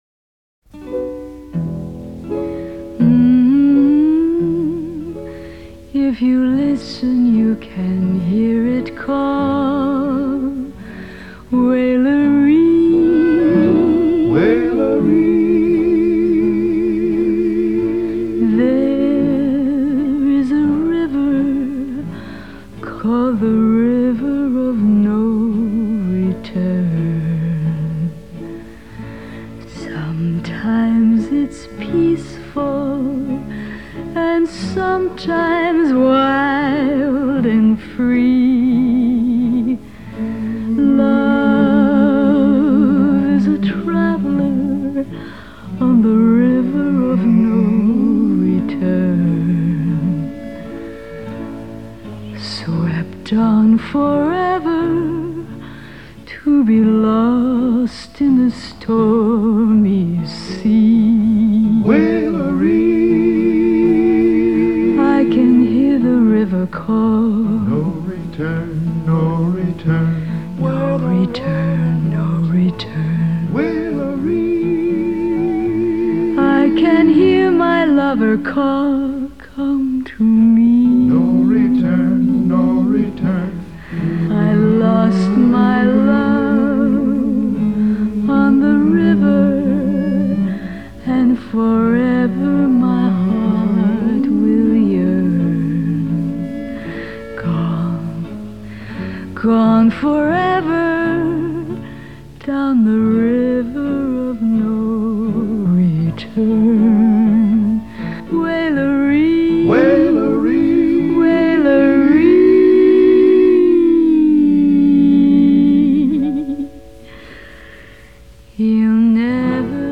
歌曲曲调优美，朗朗上口，也许您可以惬意地哼上几句！